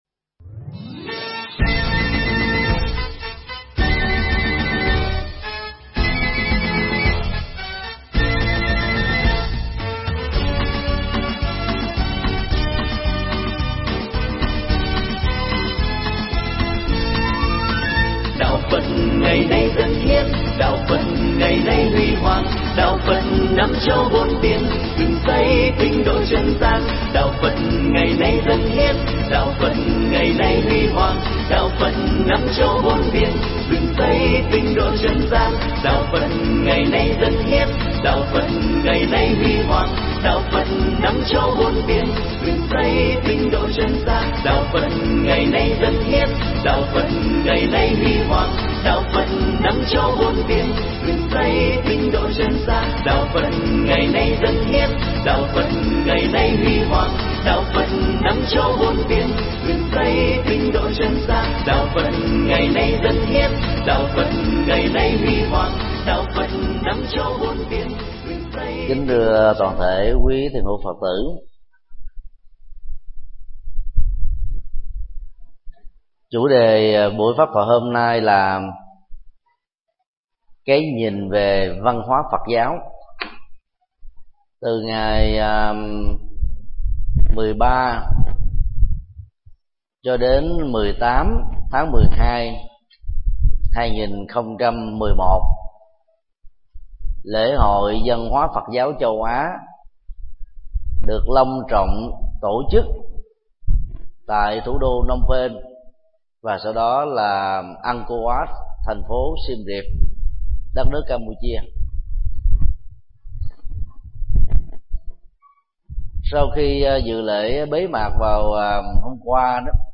Nghe mp3 bài giảng Giữ gìn văn hóa Phật giáo Việt Nam do thầy Thích Nhật Từ giảng tại chùa Xá Lợi, ngày 18 tháng 12 năm 2011.